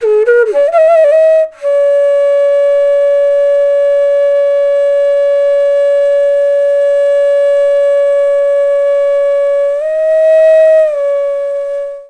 FLUTE-A08 -L.wav